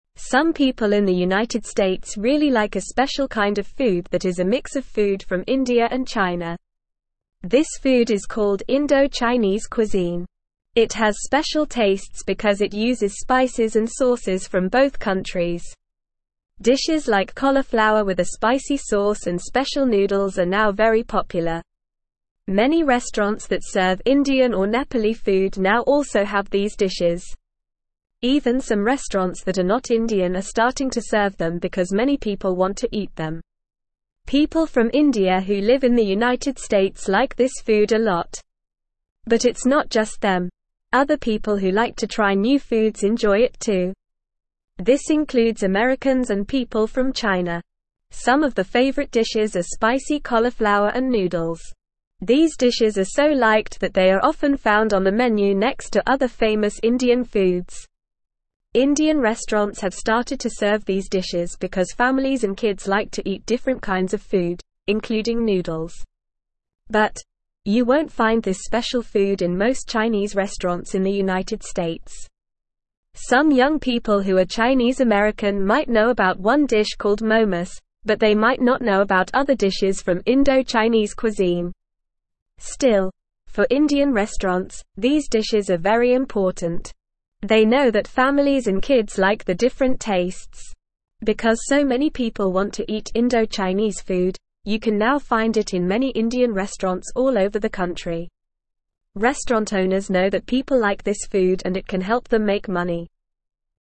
Normal
English-Newsroom-Lower-Intermediate-NORMAL-Reading-Indo-Chinese-Food-A-Tasty-Mix-of-India-and-China.mp3